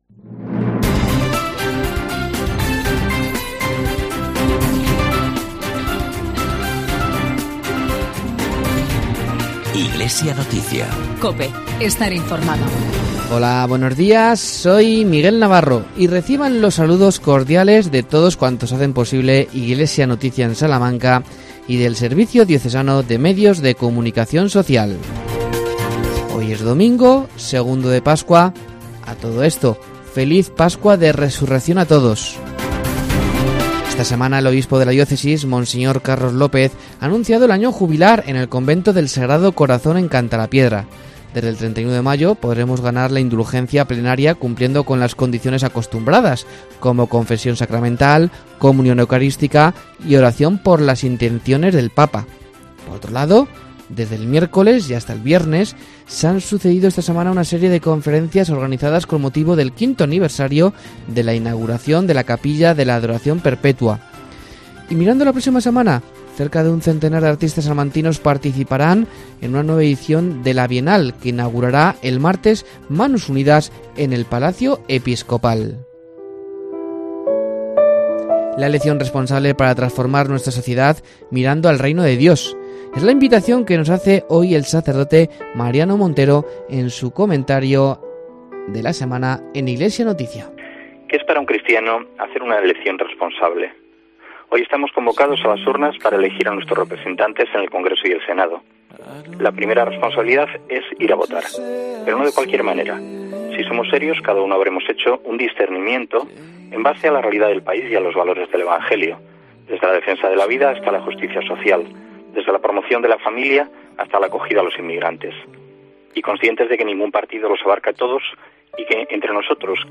AUDIO: El Obispo de Salamanca, monseñor D.Carlos López, anuncia el año jubilar en el convento de Cantalapiedra.